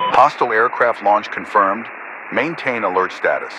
Radio-commandNewEnemyAircraft5.ogg